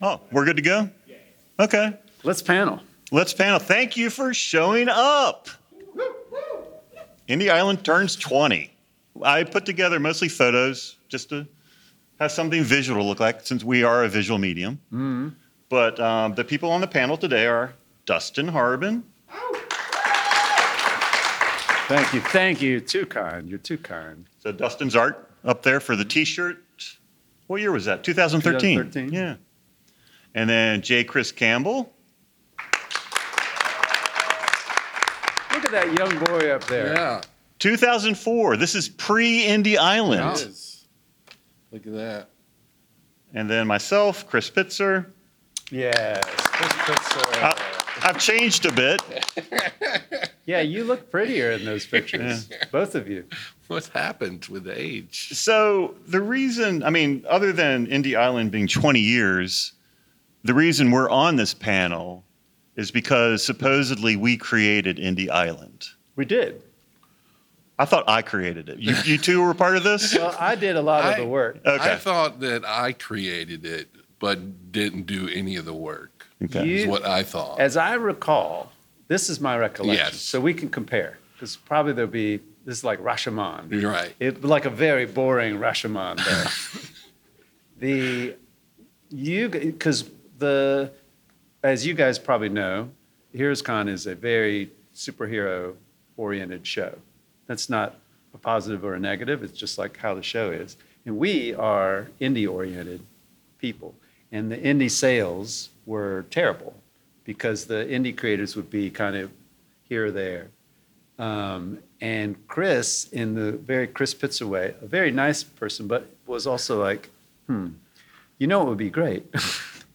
HeroesCon 2025 Panel - Indie Island 20th Anniversary — Dollar Bin Comics